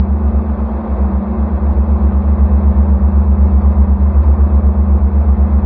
machine_loop1.wav